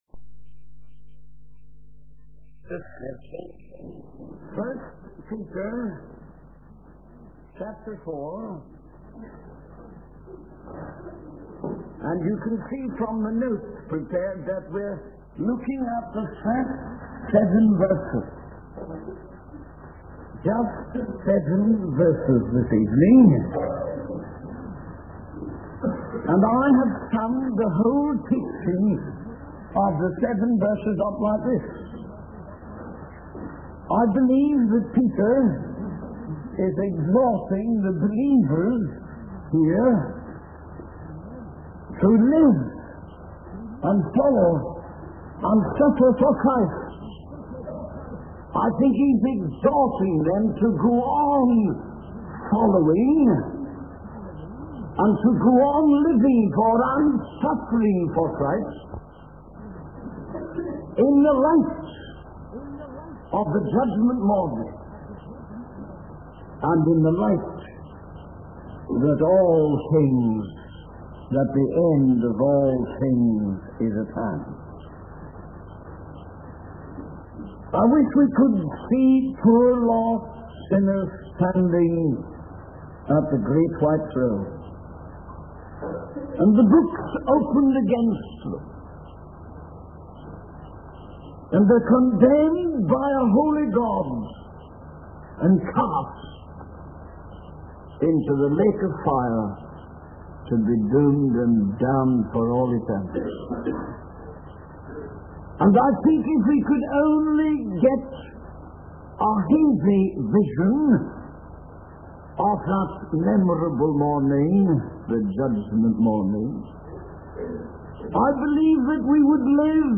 In this sermon, the preacher focuses on the seven verses of 1 Peter chapter four. He emphasizes that believers are being encouraged to live, testify, and suffer for Christ. The preacher highlights the importance of continuing to follow Christ and endure suffering in light of the coming judgment.